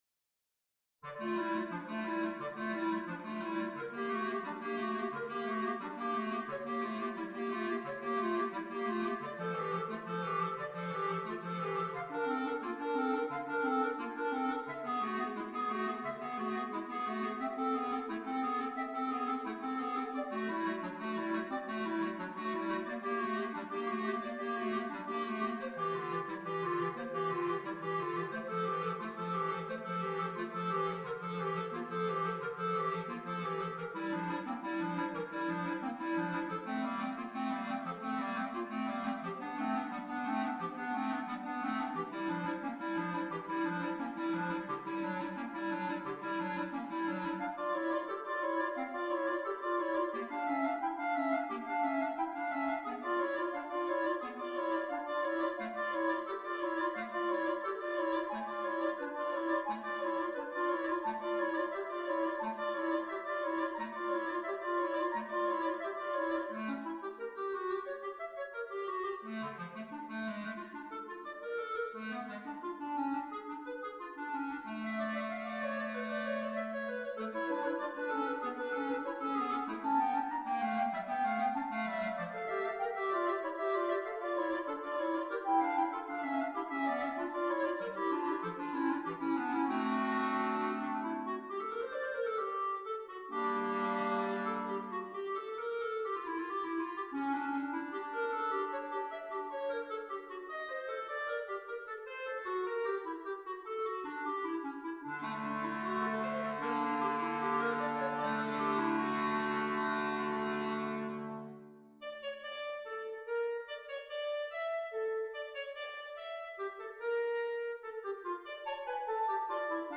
4 Clarinets